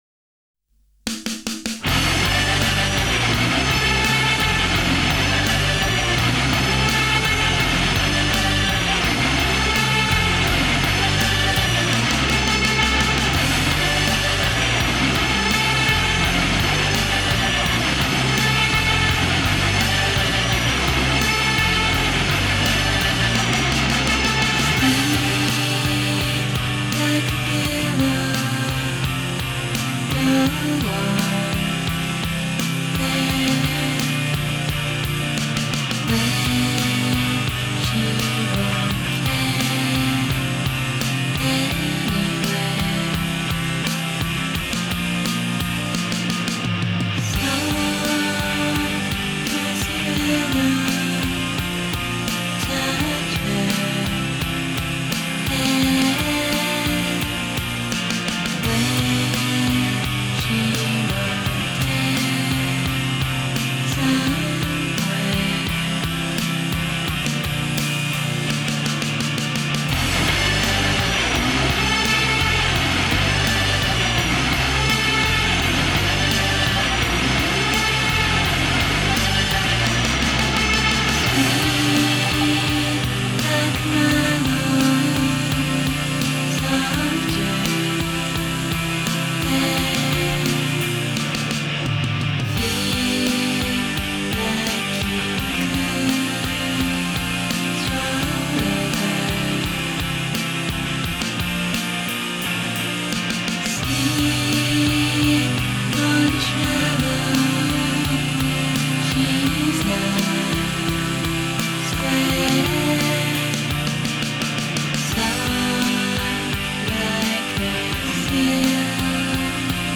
“wall of sound”.
For lovers of nineties shoe-gazers like Ride